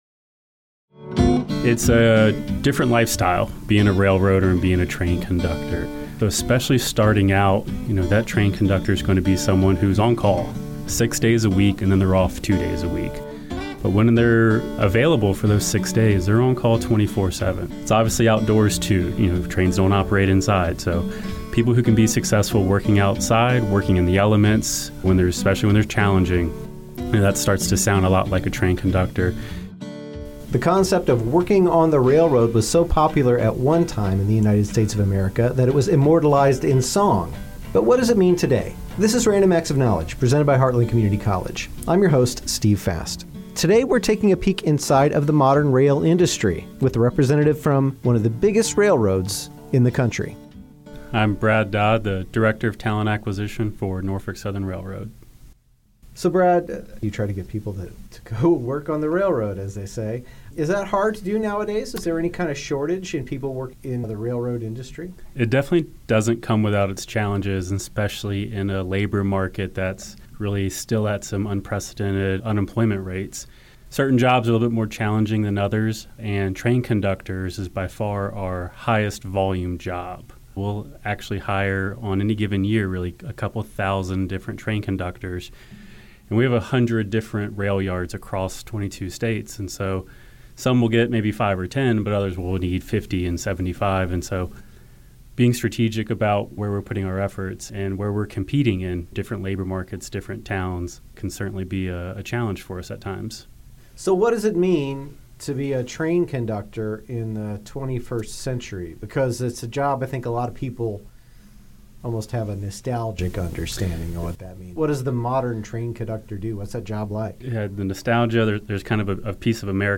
A representative from a major rail company provides some insight into the modern day life of a railroader.